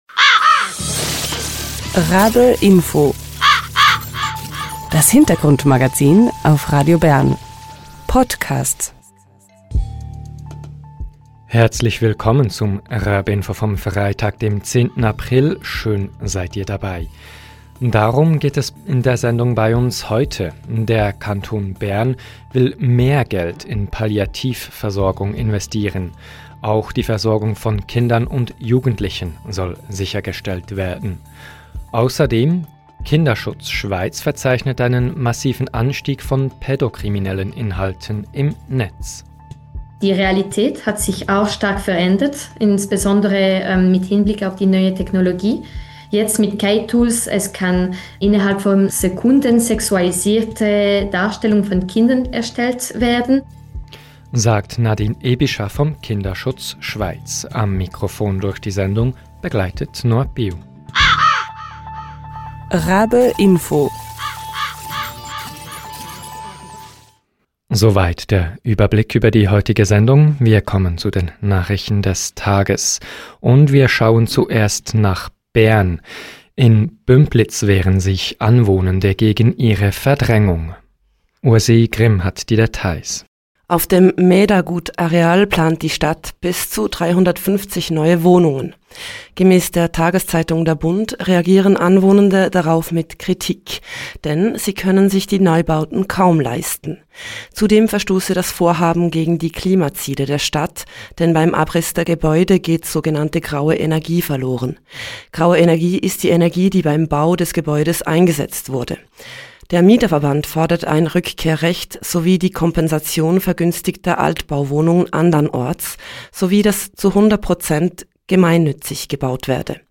Im Interview erzählt sie, was sie von der Teilstrategie hält.